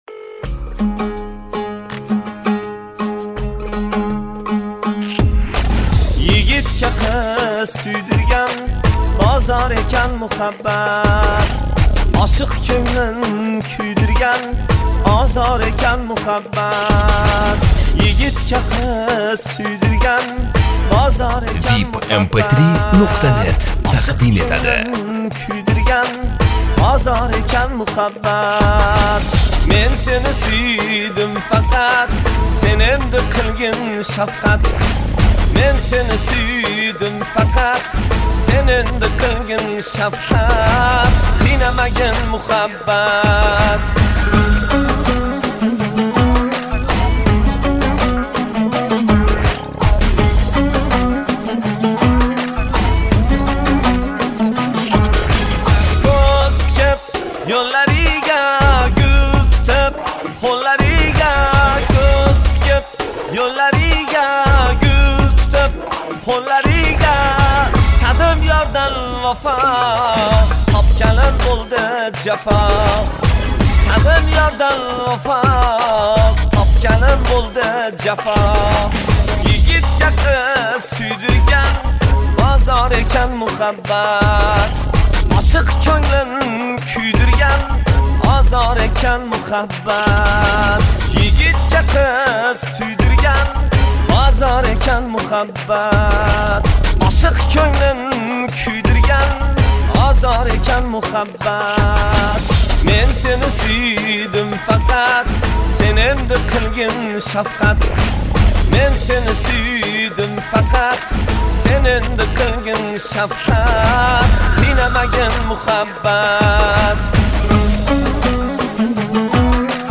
қўшиғи
Ўзбекистон мусиқаси